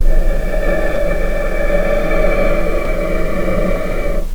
Strings / cello / ord
vc-D5-pp.AIF